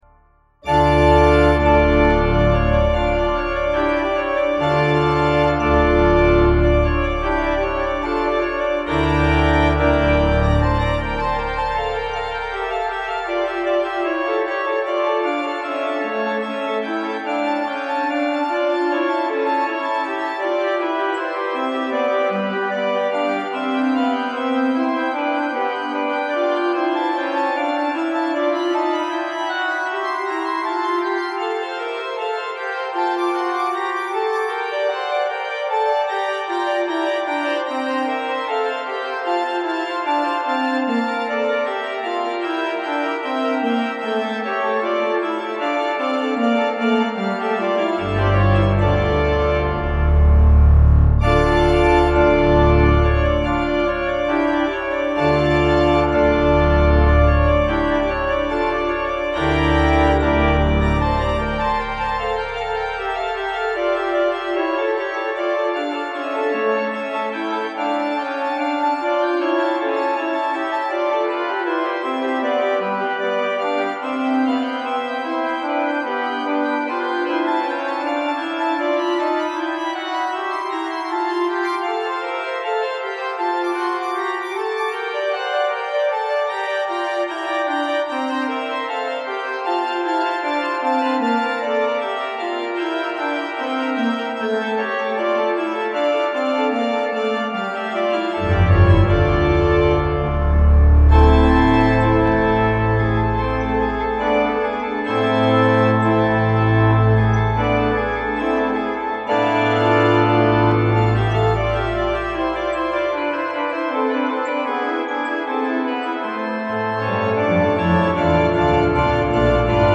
Testbericht – Organteq Kirchenorgel von Modartt
live auf seinem heimischen Orgelspieltisch eingespielt hat: